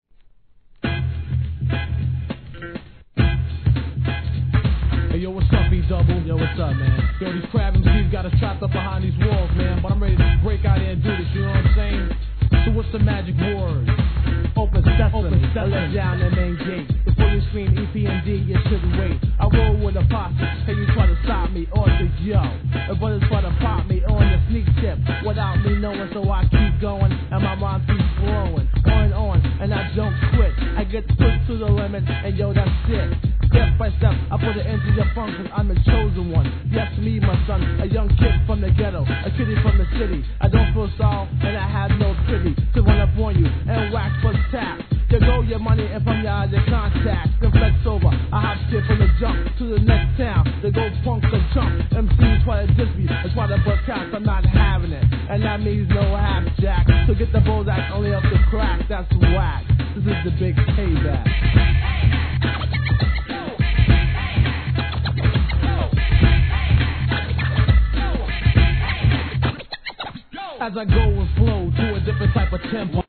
HIP HOP/R&B
フックのコスリも堪らなくエネルギッシュ!!